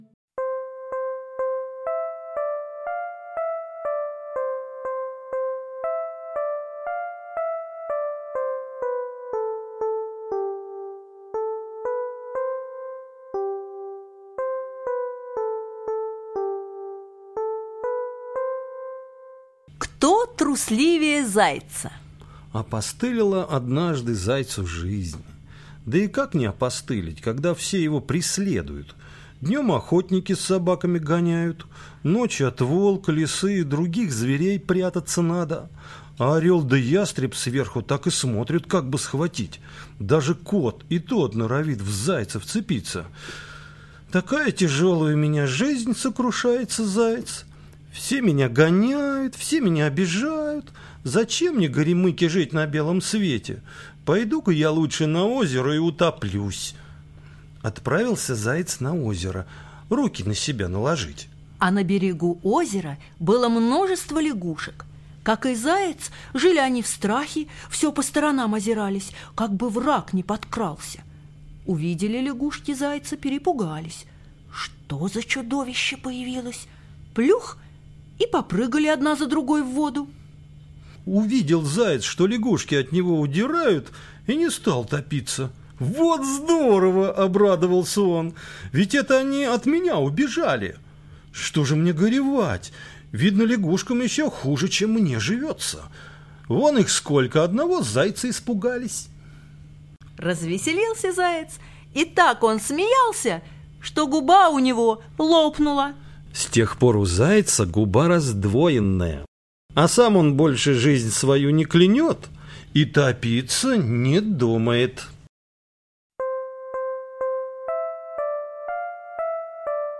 Кто трусливее зайца - латышская аудиосказка - слушать онлайн